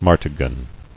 [mar·ta·gon]